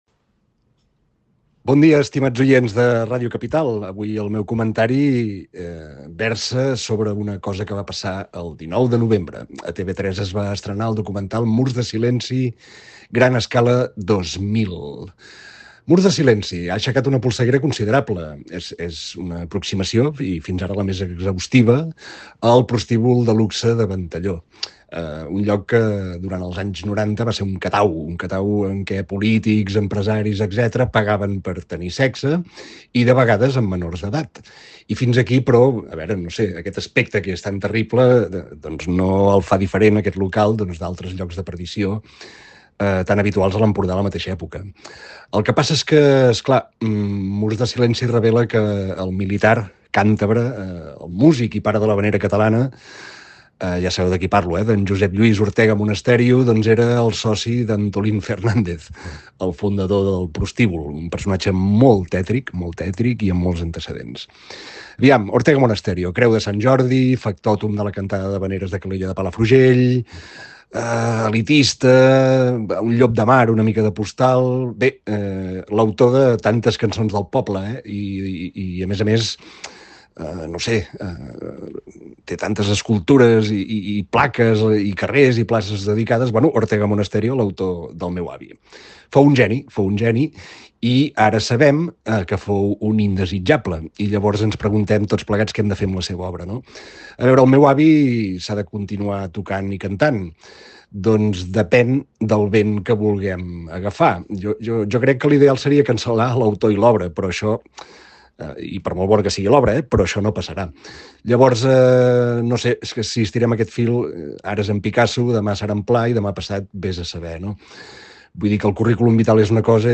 Opinió